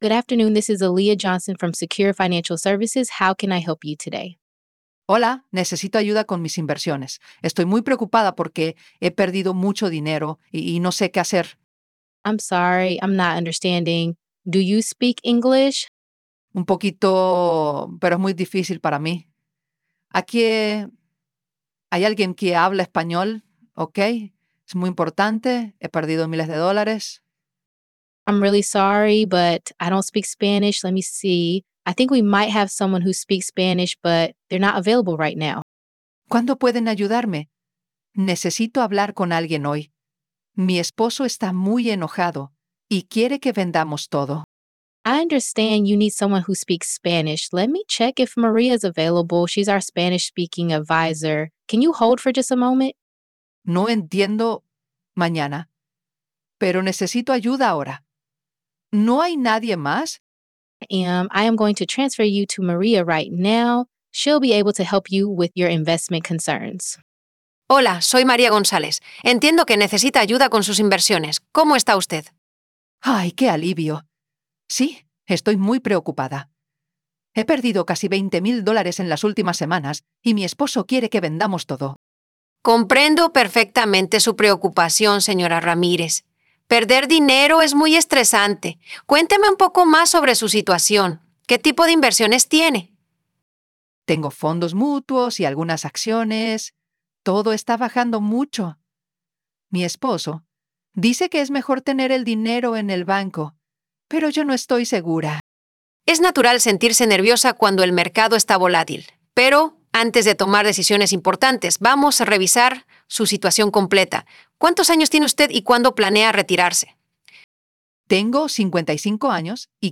an audio file an audio file with two speakers, one speaking English and the other Spanish.
consultation_5_mix_es_en.wav